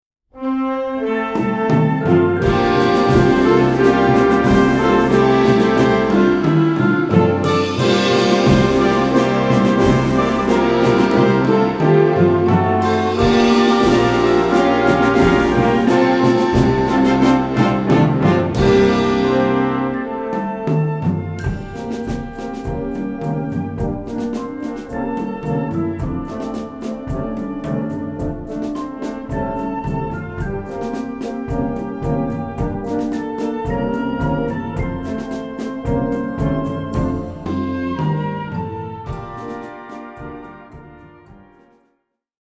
Wind Band (harmonie)
Easy Listening / Unterhaltung / Variété
Young Band/Jugend Band/Musique de jeunes